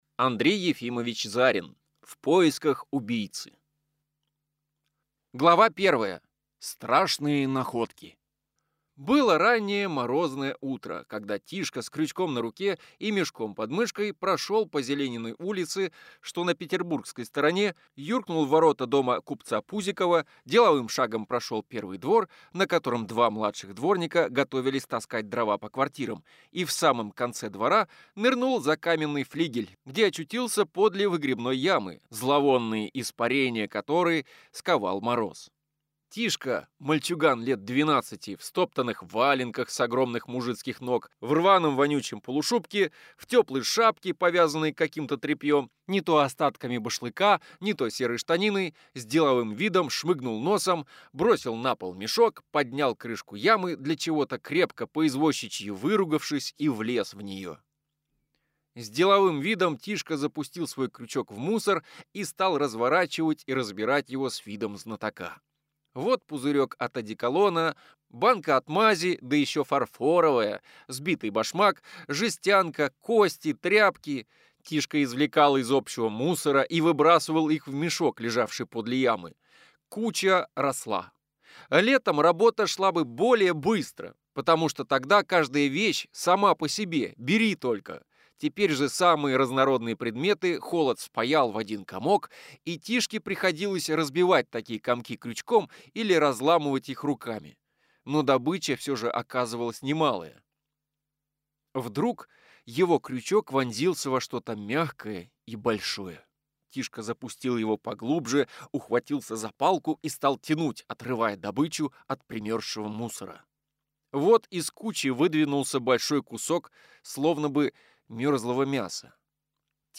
Аудиокнига В поисках убийцы | Библиотека аудиокниг